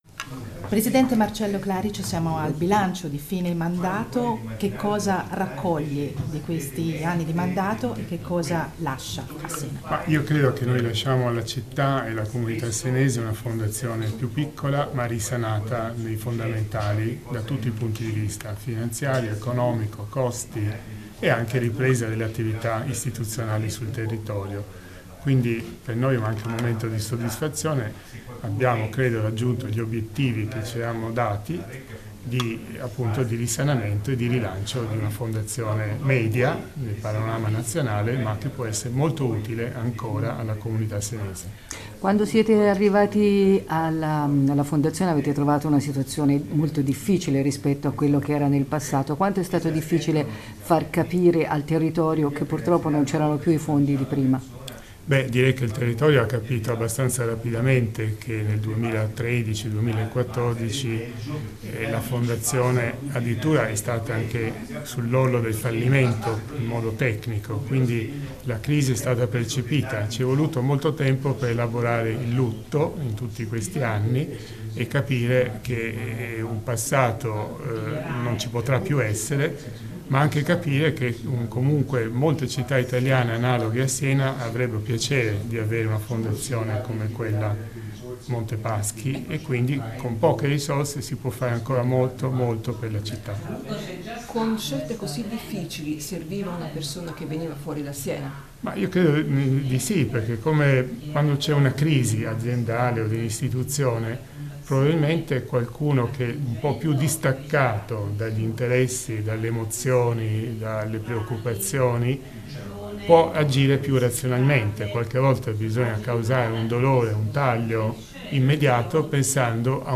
Resoconto di fine mandato per la Deputazione della Fondazione Mps. Intervista